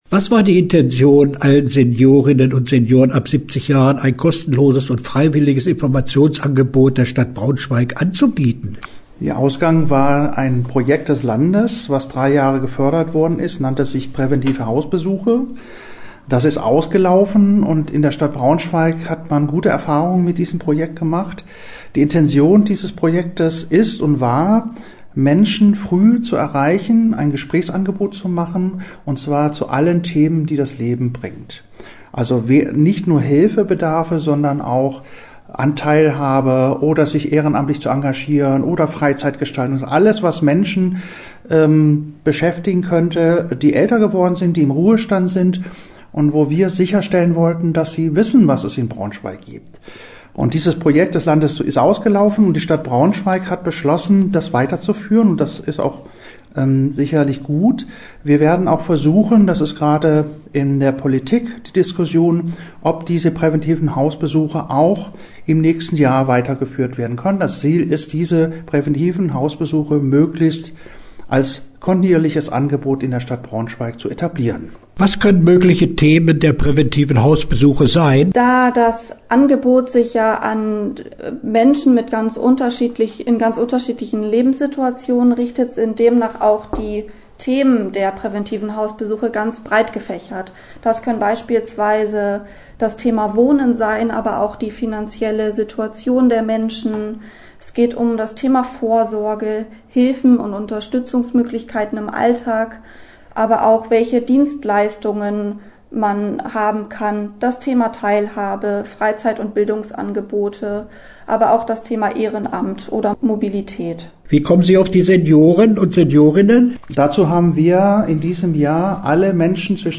Interview-Praeventive-Hausbesuche.mp3